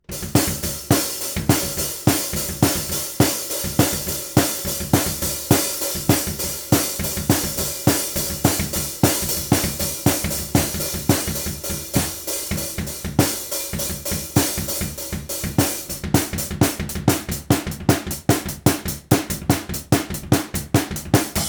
navy_drums_bypassed.flac